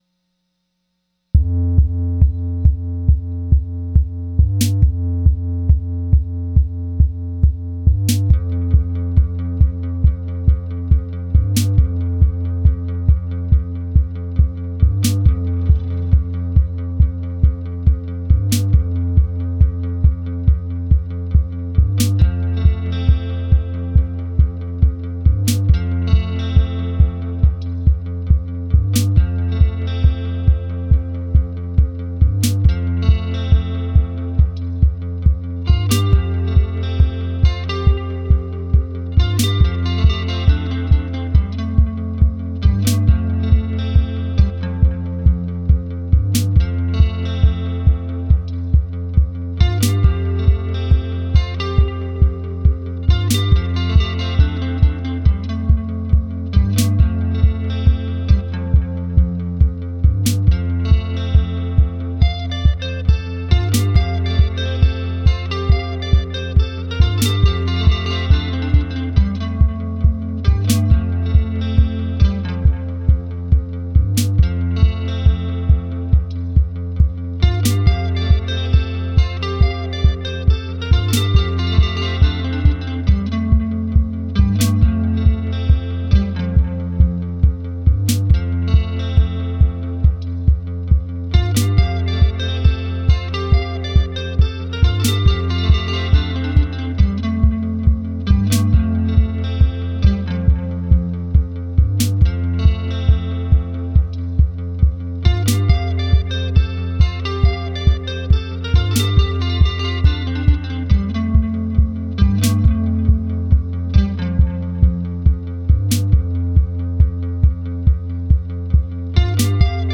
Nothing fancy here, just looping with the Deluge and removing the rust from my guitar playing skills.